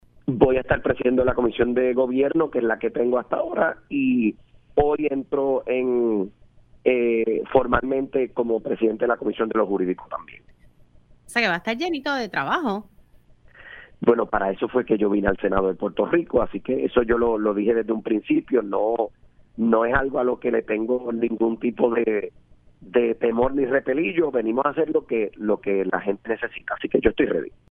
El senador Ángel Toledo reveló en Pega’os en la Mañana que bajo la presidencia de Roxanna Soto, la Comisión de lo Jurídico sólo atendió el 3 por ciento de las medidas que llegaron durante esta sesión legislativa.